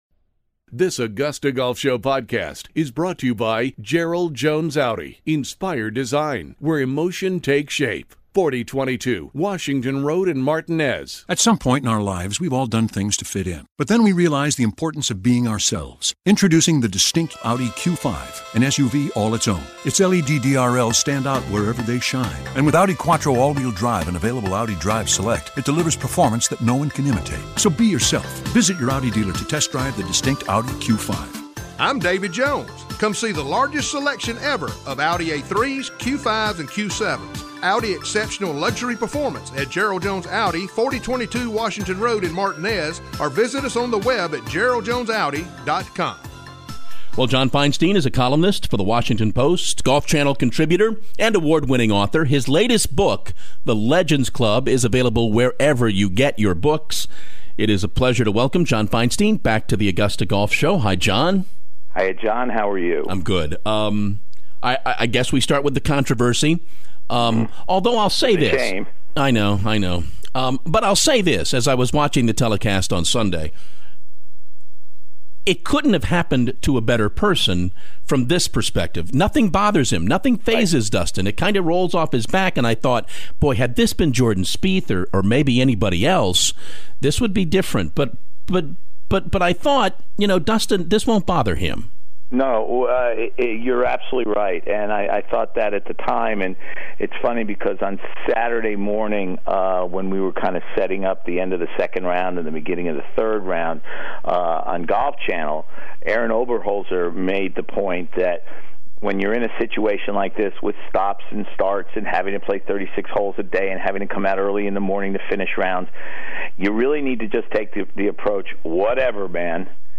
John Feinstein: The Augusta Golf Show Interview
Washington Post columnist, Golf Channel contributor and award winning author John Feinstein is on the show to recap the 2016 US Open, what it means for the USGA and for Dustin Johnson going forward